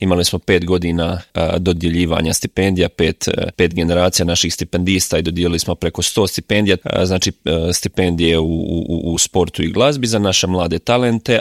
Kako je došao na ideju osnivanja zaklade, Marin je u intervjuu Media servisa rekao: